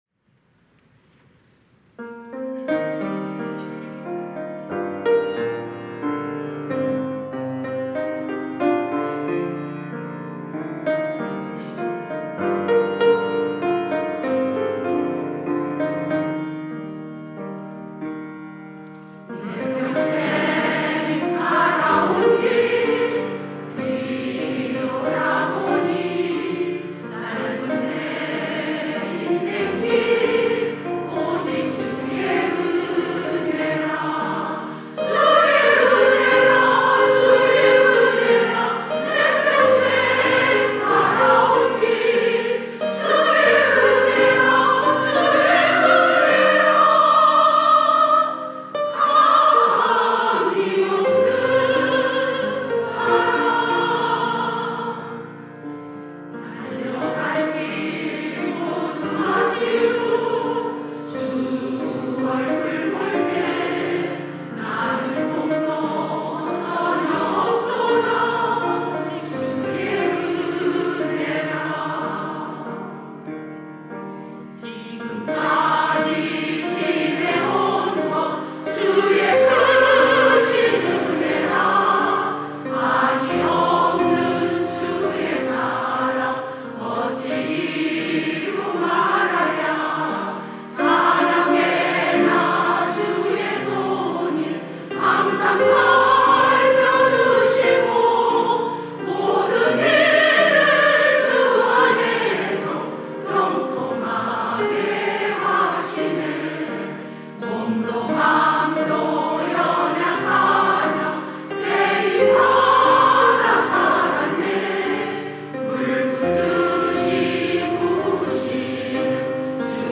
특송과 특주 - 주의 은혜라
시니어대학 찬양대